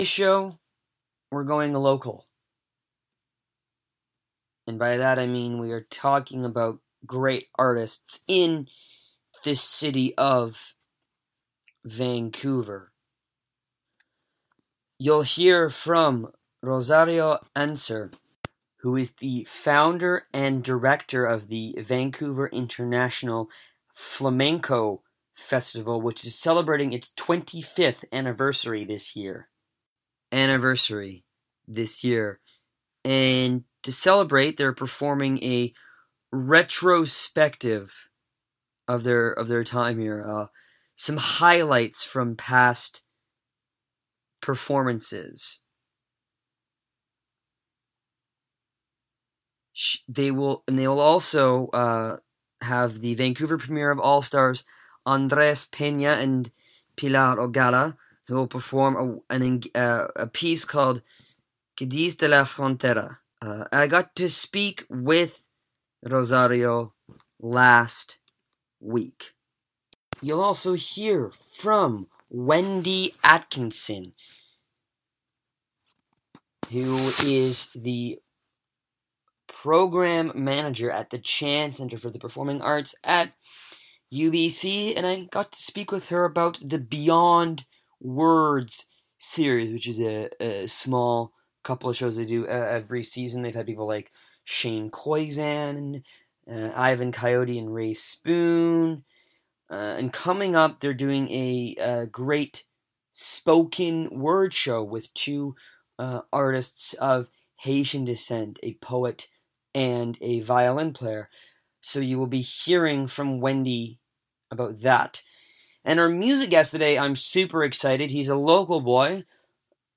Endeavours - Flamenco and Spoken Word